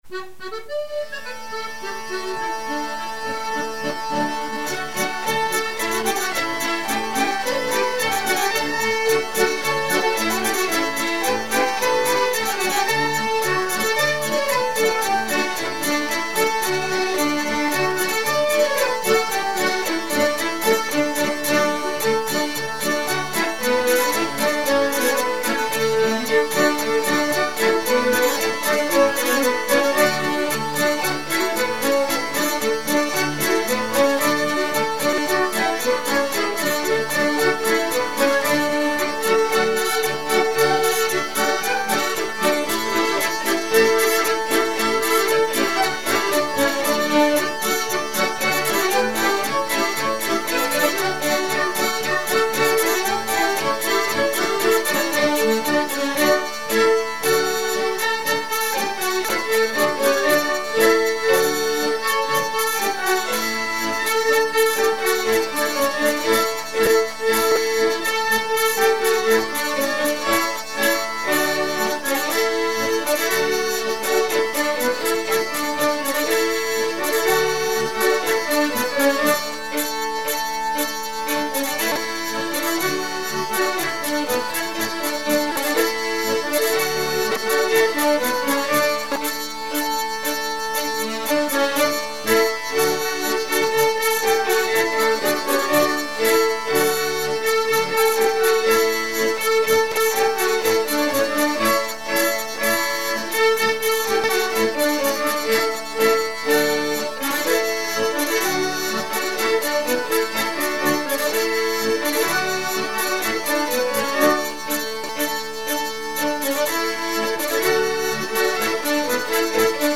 Marais Breton Vendéen
danse : polka